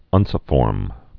(ŭnsə-fôrm)